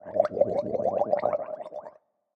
Minecraft Version Minecraft Version snapshot Latest Release | Latest Snapshot snapshot / assets / minecraft / sounds / mob / drowned / water / idle1.ogg Compare With Compare With Latest Release | Latest Snapshot